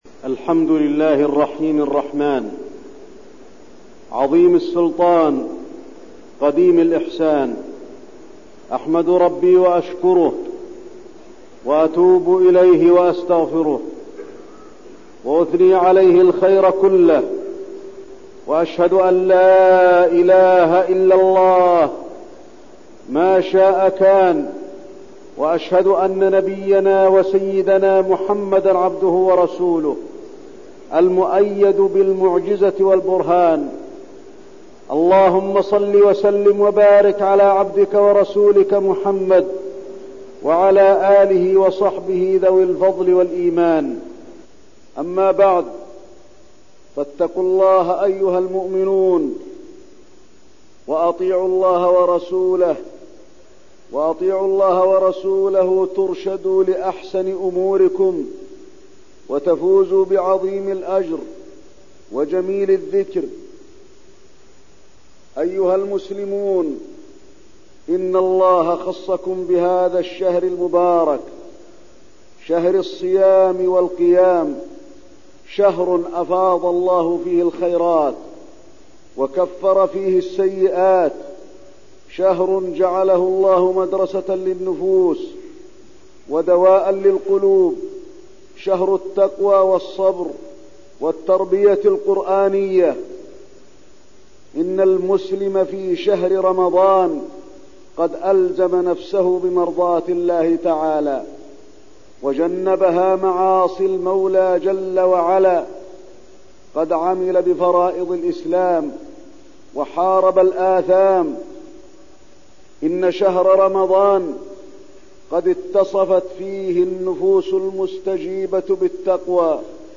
تاريخ النشر ٢٣ رمضان ١٤١٢ هـ المكان: المسجد النبوي الشيخ: فضيلة الشيخ د. علي بن عبدالرحمن الحذيفي فضيلة الشيخ د. علي بن عبدالرحمن الحذيفي خروج شهر رمضان